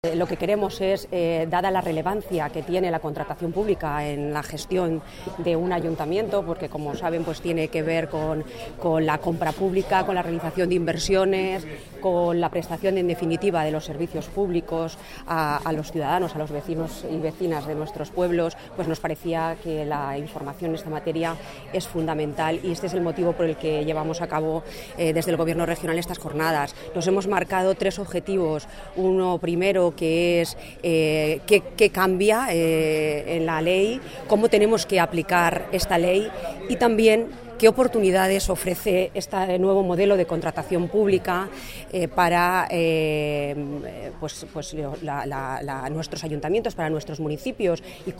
La viceconsejera de Administración Local y Coordinación Administrativa, Pilar Cuevas ha explicado hoy